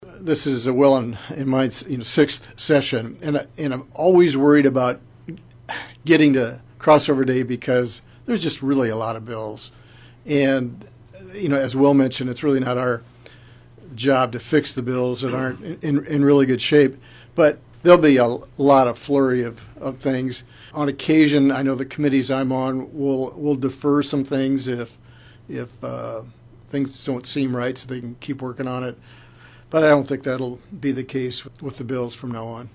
District 24 Representative Mike Weisgram says over 600 bill have been dropped this year….